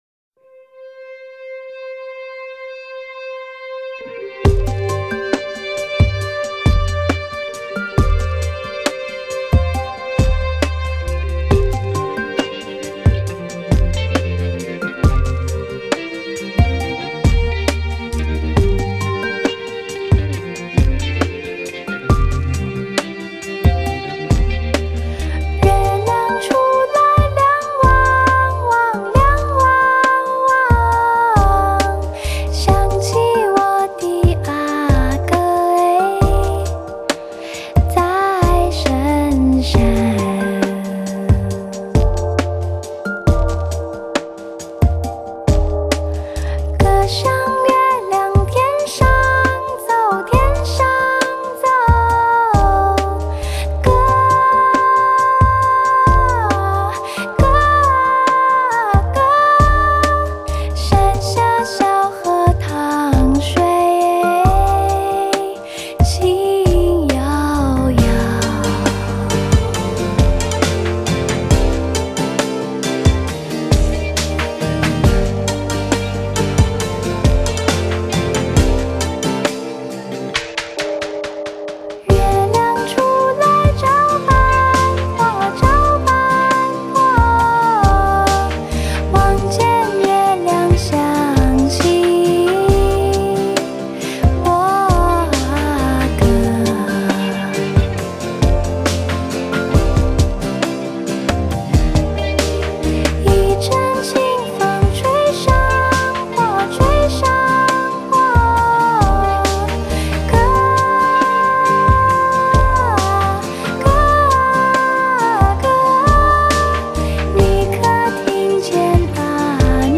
歌喉里淌出的 小河水